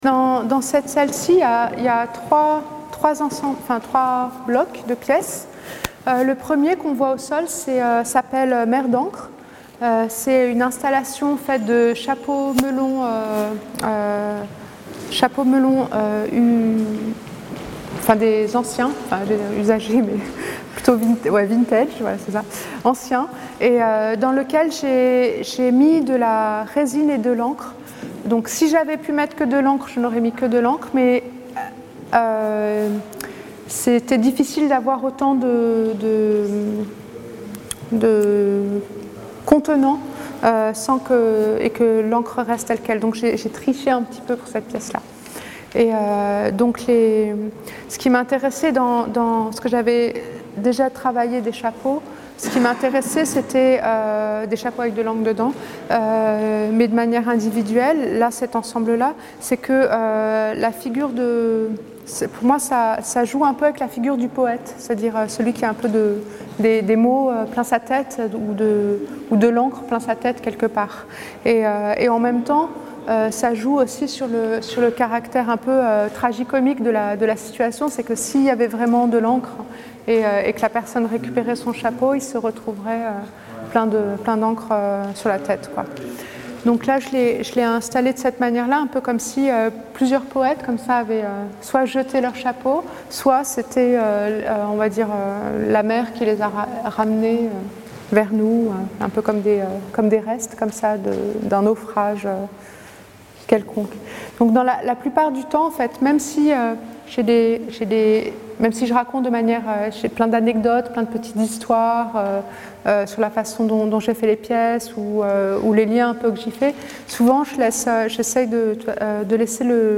Le macLYON a proposé à Latifa Echakhch de parler de chacune des œuvres présentes dans l'exposition Laps, et a intégré ces séquences sonores à ses cartels.